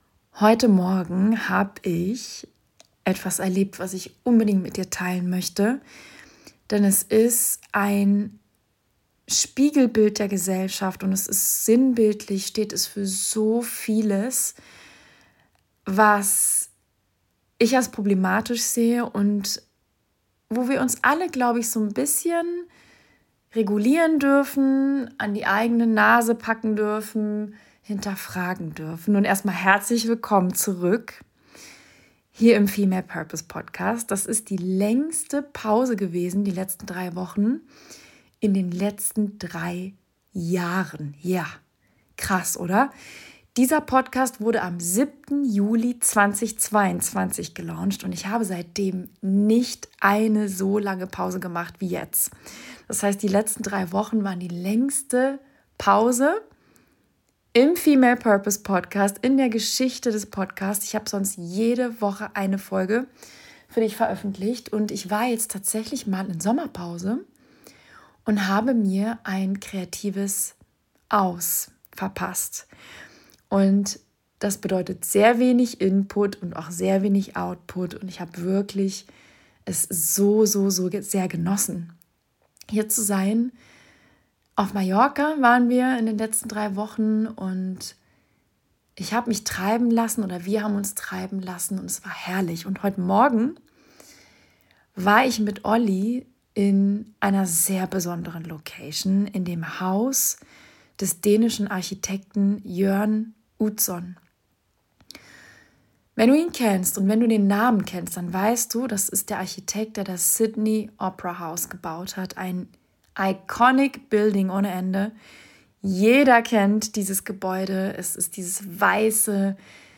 Real, raw und ungeschnitten.